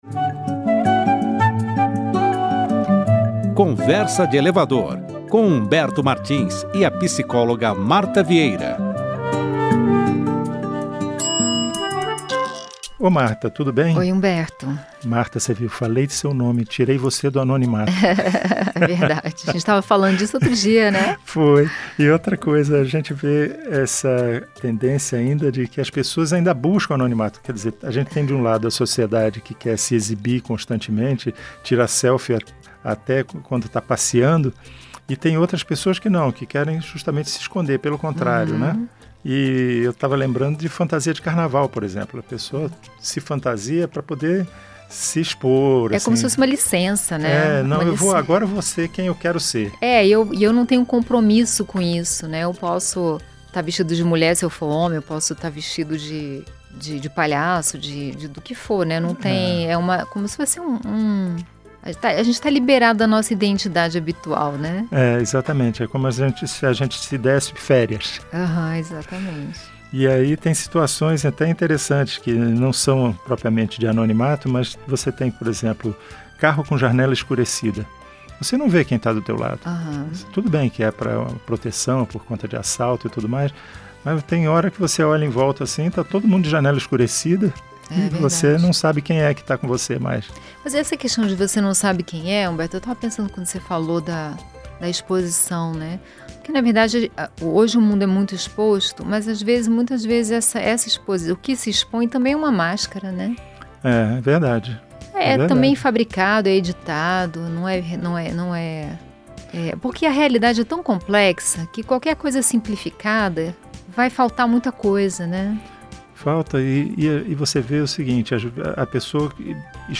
Um bate-papo leve e divertido sobre temas do cotidiano, mas com uma pitada de reflexão sobre psicologia, literatura e filosofia.